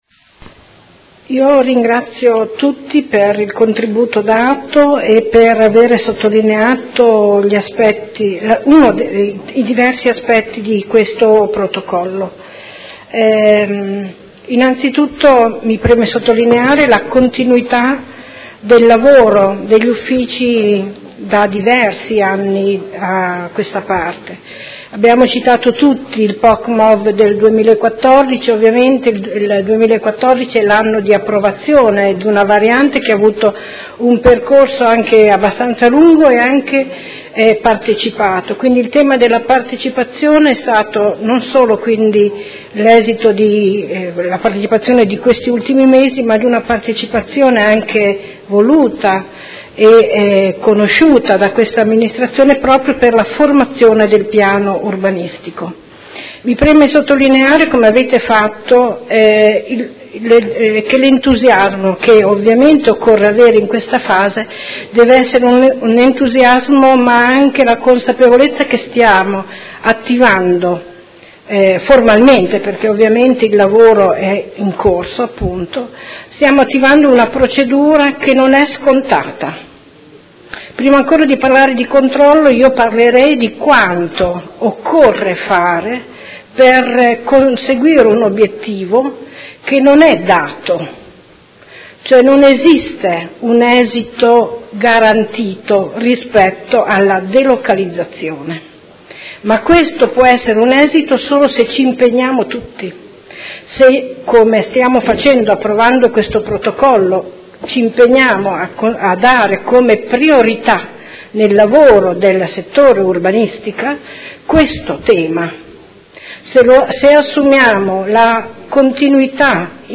Anna Maria Vandelli — Sito Audio Consiglio Comunale